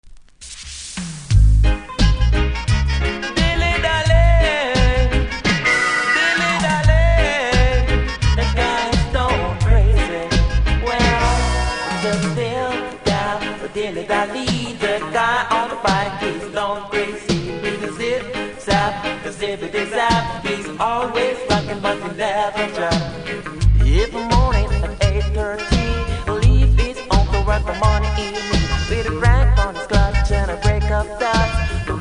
REGGAE 80'S